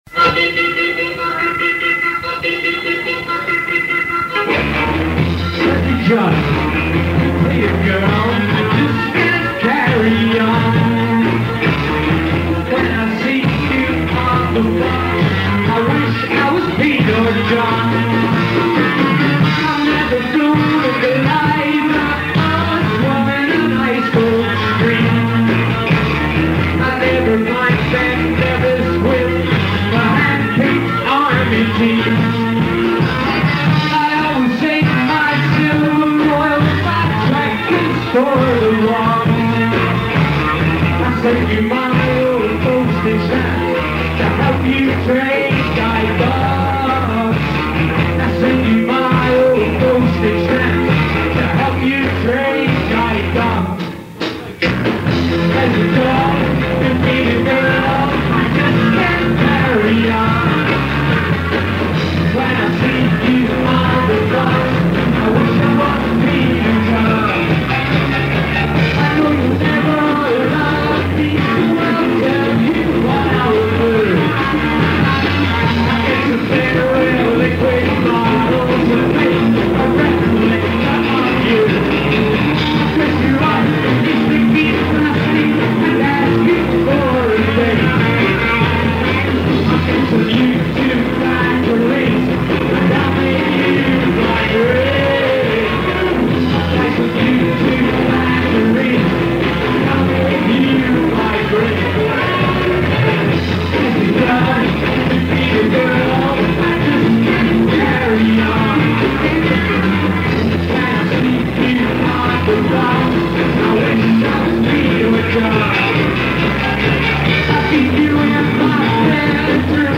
The Brecknock, Camden Rd,